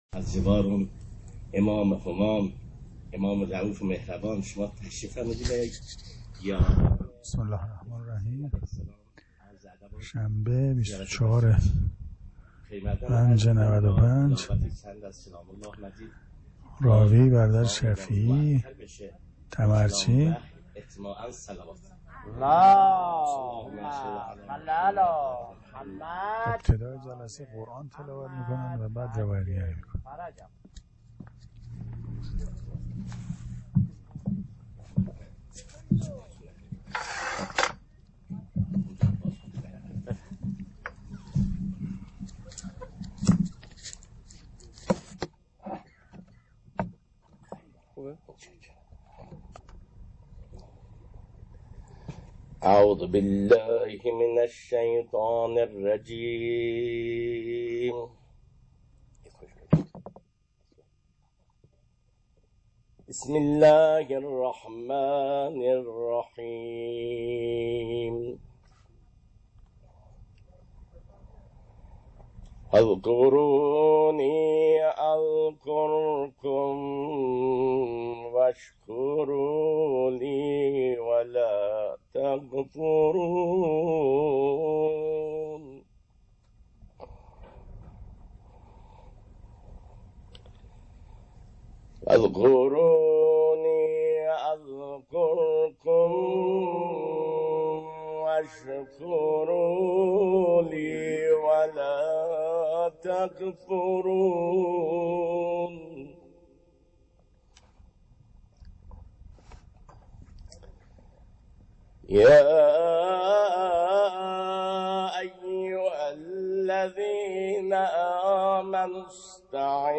راوی دوران دفاع مقدس